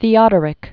(thē-ŏdər-ĭk) Known as "the Great."